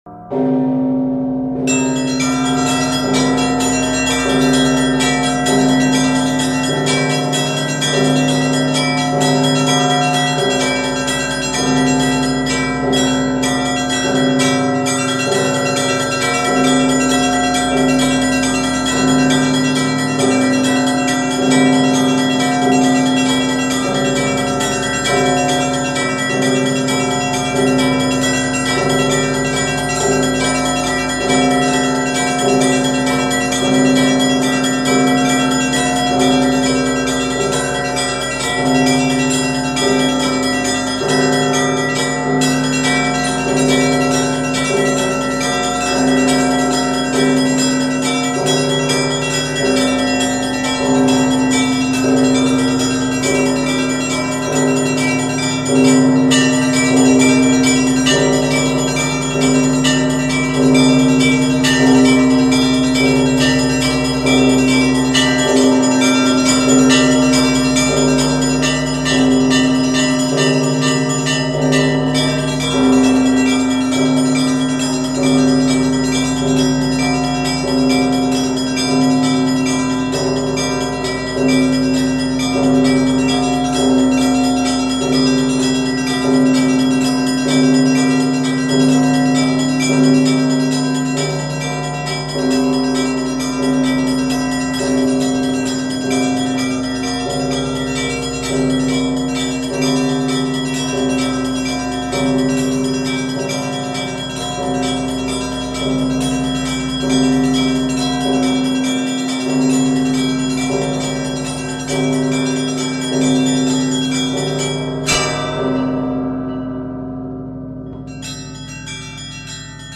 Звуки колокола
Пасхальный перезвон колоколов